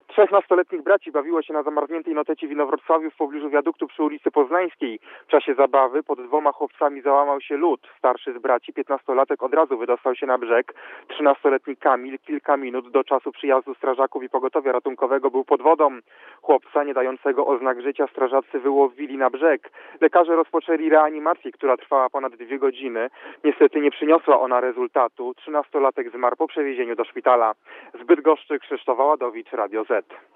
O tragicznym wypadku opowiada reporter Radia Zet